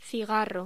Locución: Cigarro